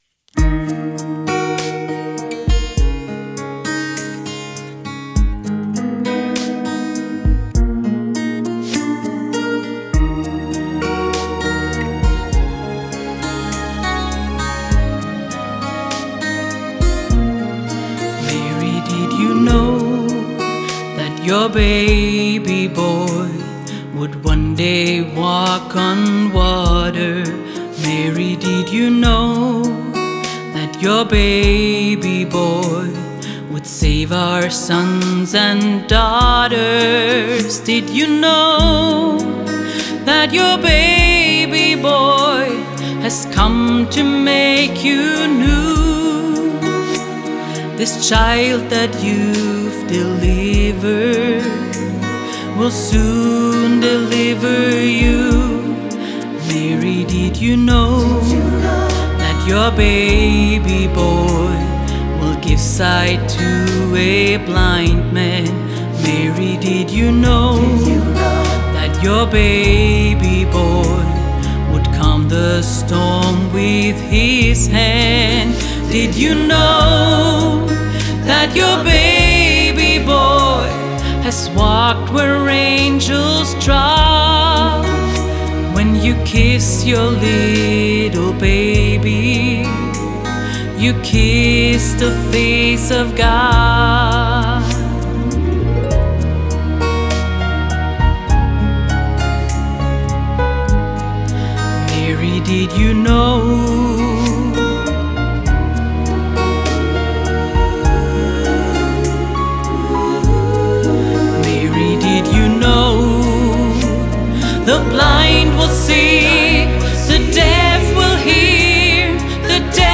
Jede Woche singen wir für den guten Zweck!